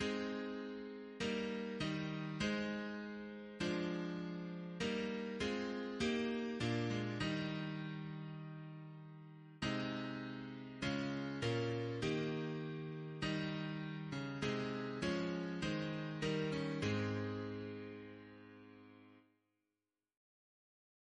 Double chant in A♭ Composer: Sydney Bevan (1838-1901) Note: tune known as Trent Reference psalters: ACB: 230; CWP: 92; OCB: 5; PP/SNCB: 10; RSCM: 136